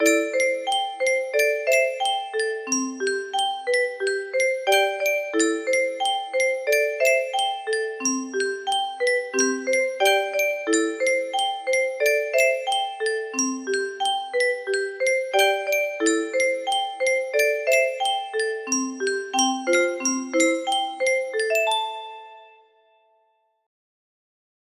Kikkerland 15 music boxes More